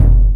Kick 12.wav